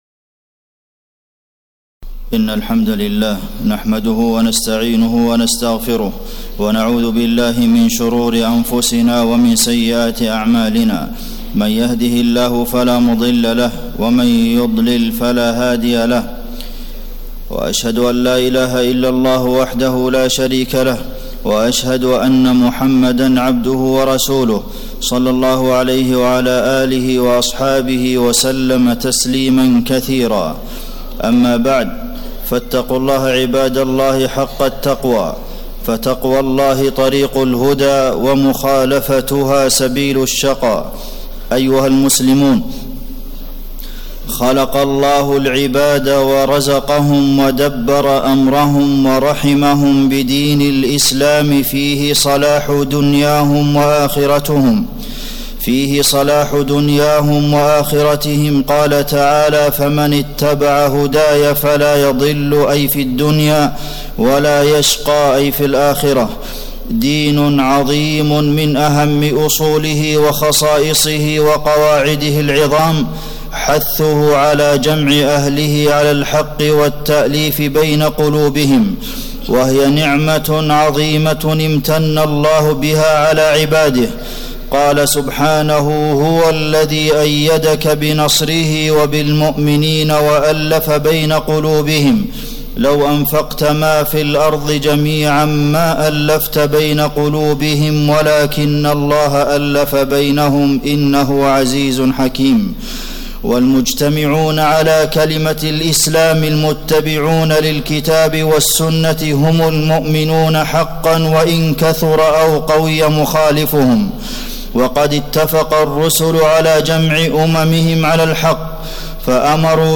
تاريخ النشر ٢٩ ذو الحجة ١٤٣٧ هـ المكان: المسجد النبوي الشيخ: فضيلة الشيخ د. عبدالمحسن بن محمد القاسم فضيلة الشيخ د. عبدالمحسن بن محمد القاسم فضل الإجتماع والإئتلاف